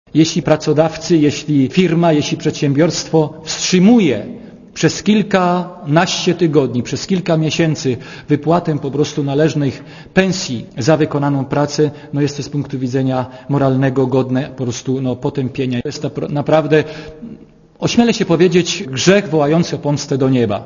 Biskupi zgodnie uznali że najbardziej bulwersujące jest wstrzymywanie pensji przez pracodawców - mówił sekretarz generalny episkopatu Polski biskup Piotr Libera